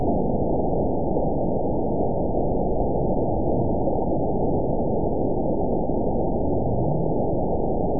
event 920322 date 03/15/24 time 21:30:49 GMT (1 year, 1 month ago) score 9.41 location TSS-AB01 detected by nrw target species NRW annotations +NRW Spectrogram: Frequency (kHz) vs. Time (s) audio not available .wav